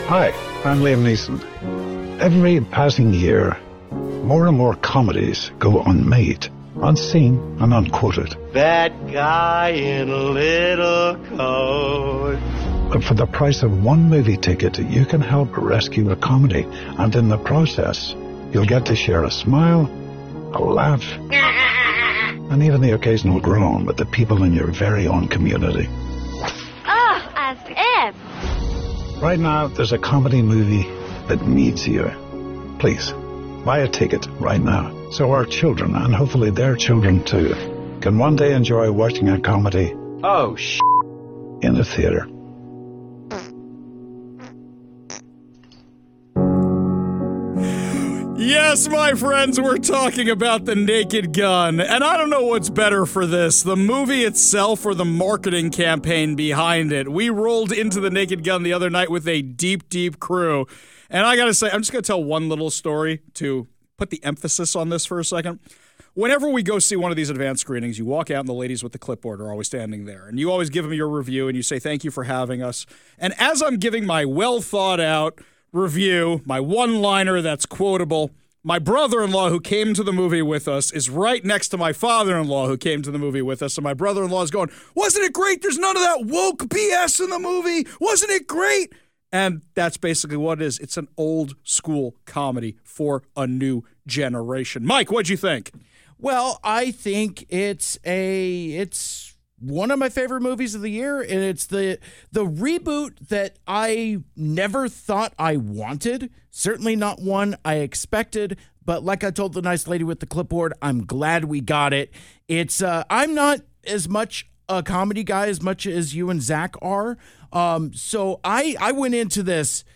With no heavy-handed agendas, this standalone flick delivers clever, scathing slapstick that had the KVI crew laughing non-stop.